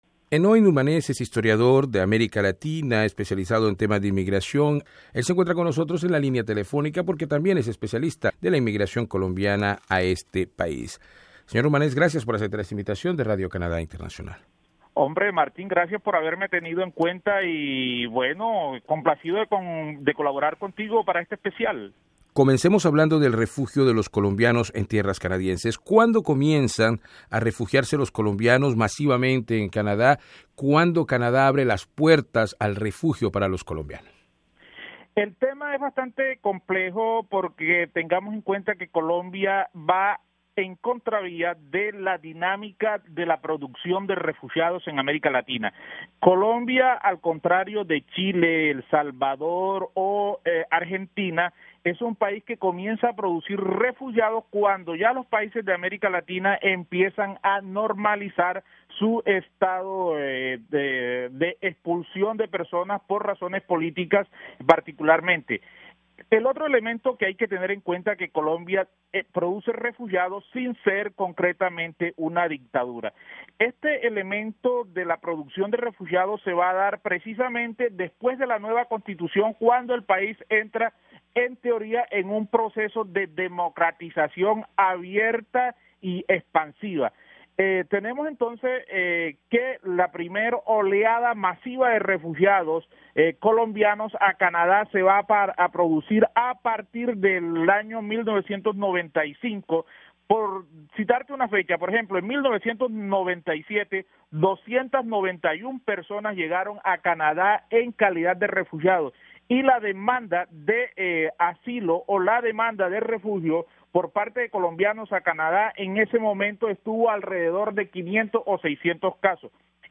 En esta entrevista, el historiador describe las causas que llevaron a esos miles colombianos a pedir asilo en Canadá y la evolución de la apertura que –en algún momento- las autoridades canadienses tuvieron con los solicitantes de refugio procedentes de Colombia.